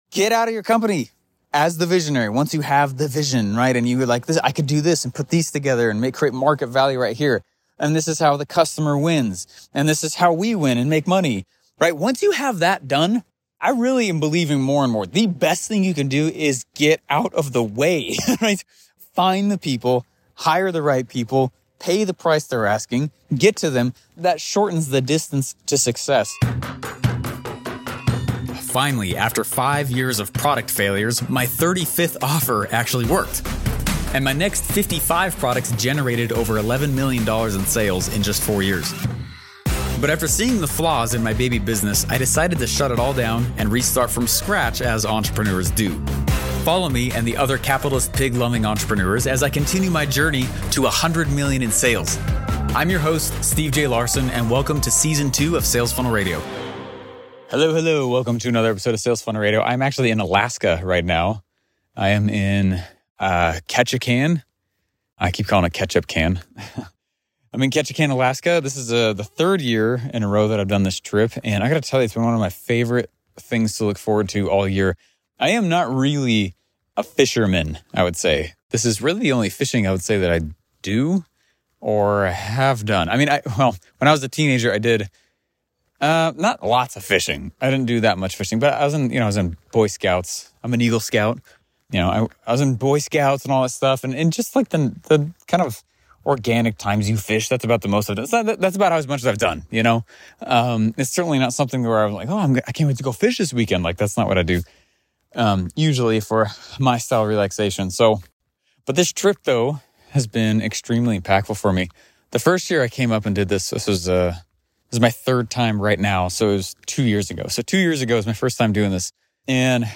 I recorded this while fishing in Alaska.